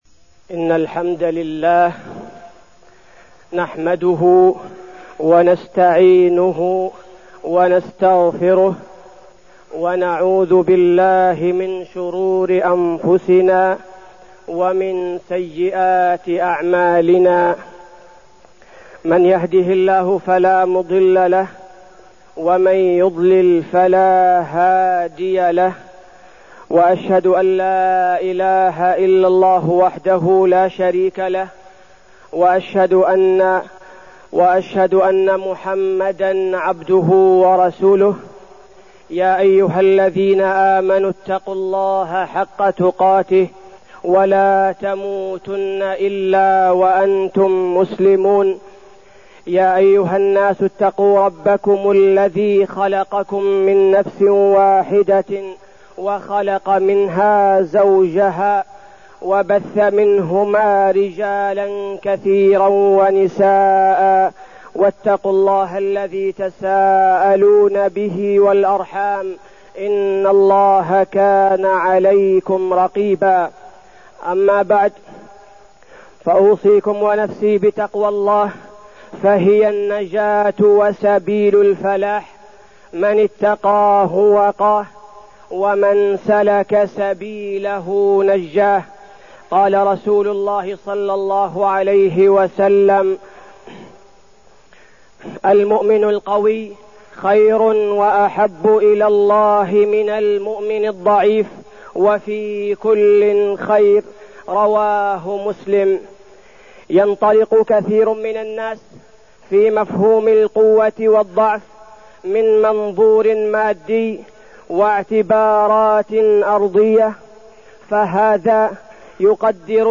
تاريخ النشر ١٢ جمادى الآخرة ١٤١٩ هـ المكان: المسجد النبوي الشيخ: فضيلة الشيخ عبدالباري الثبيتي فضيلة الشيخ عبدالباري الثبيتي قوة الإيمان